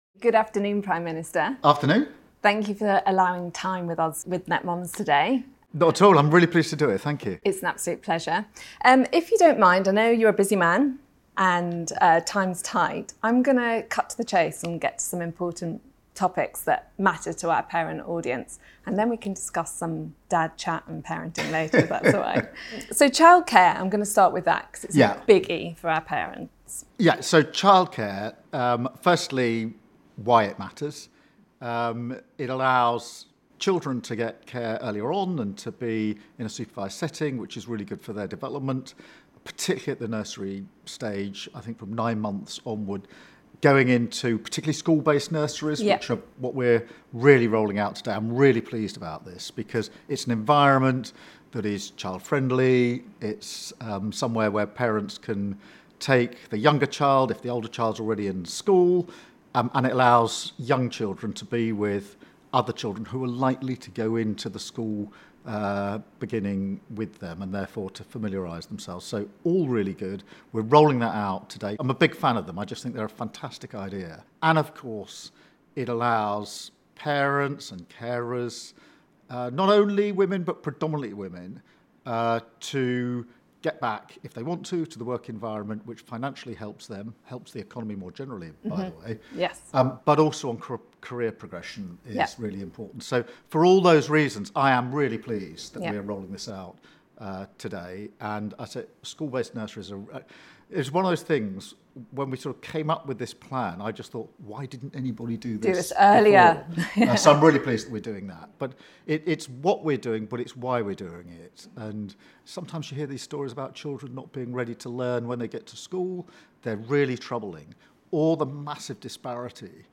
In this exclusive episode of the Netmums Podcast, we’re invited inside 10 Downing Street for a rare, one-to-one interview with Prime Minister Keir Starmer — and we’re talking about more than politics.